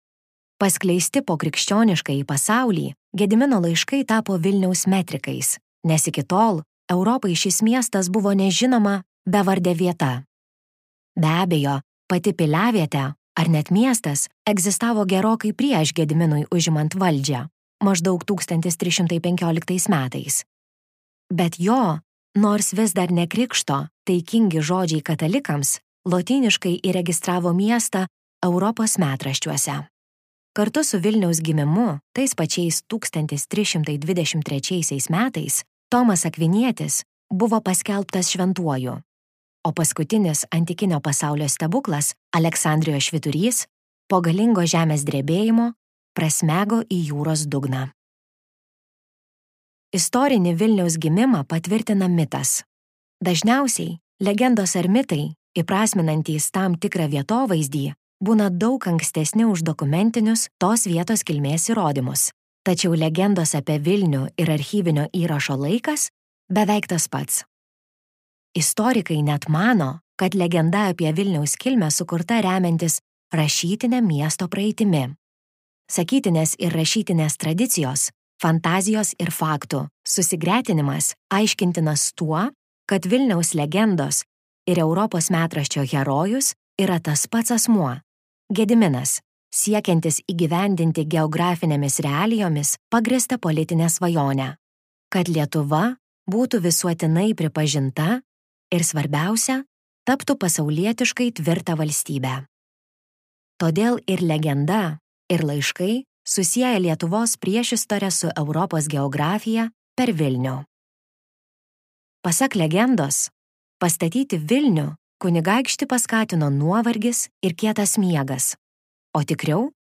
audioknyga